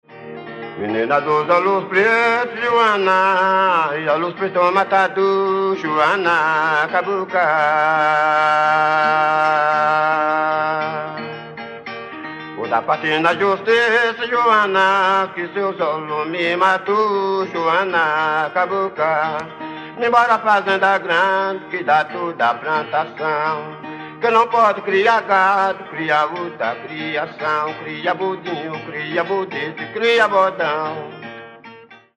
Moda-de-viola
Atividade musical lúdica que se caracteriza por forma estrófica, ausência de metrificação musical (ritmo livre), versos narrativos e execução por dois cantores em terças ou sextas paralelas.
modadeviola.mp3